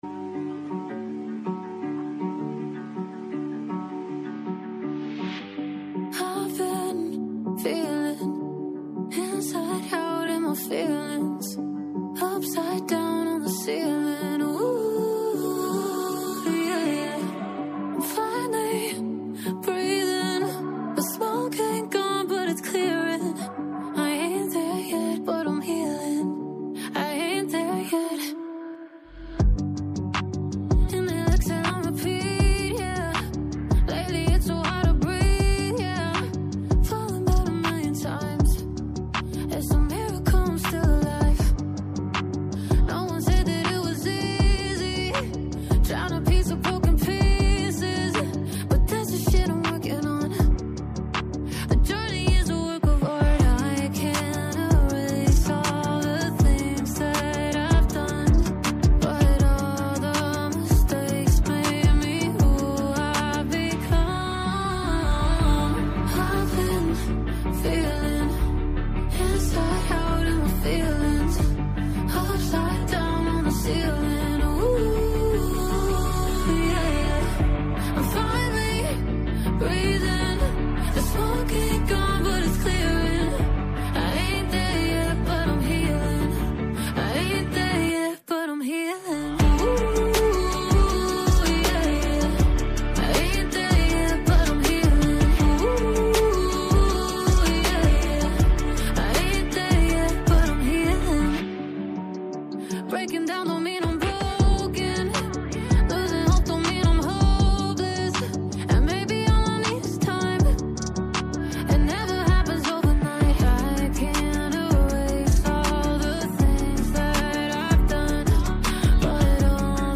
Καλεσμένη στο στούντιο